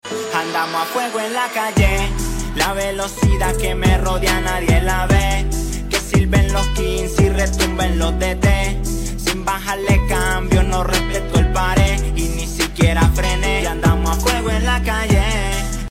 Team and at small bore scooter motorcycle event 4 stroke motorcycle & scooter